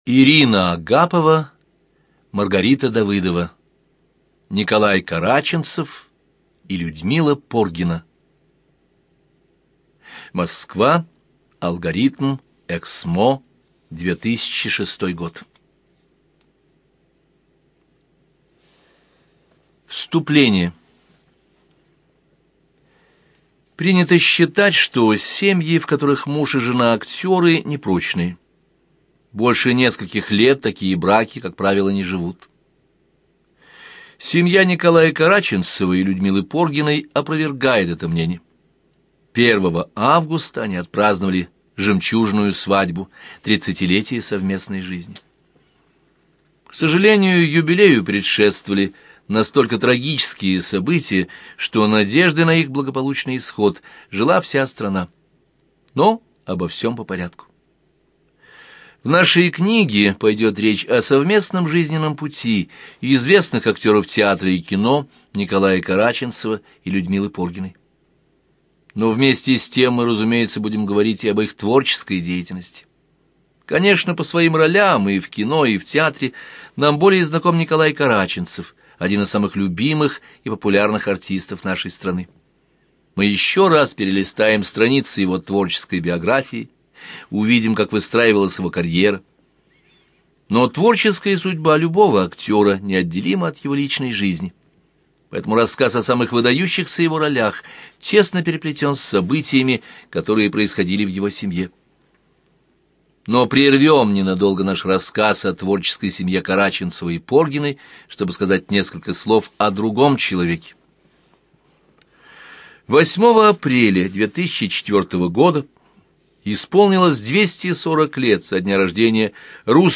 ЖанрБиографии и мемуары
Студия звукозаписиЛогосвос